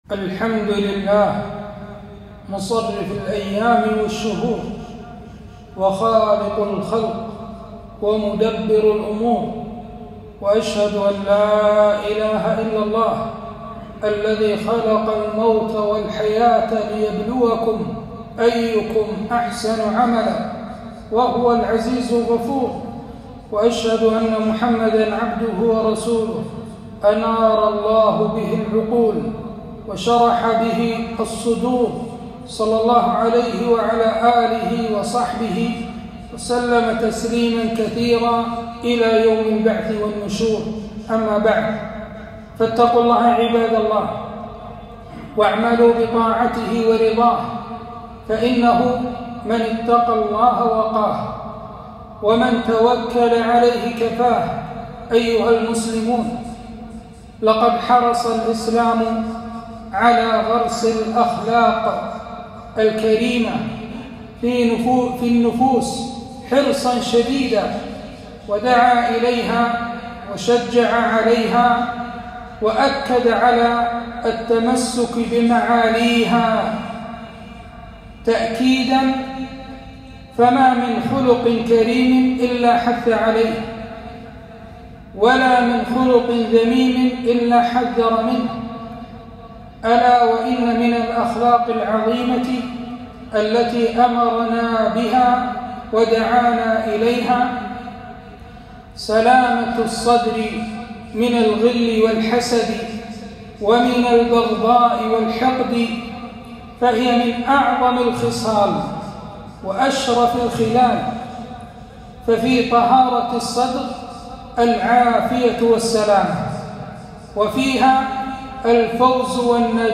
خطبة - سلامة الصدر